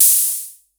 Vermona Open Hat 04.wav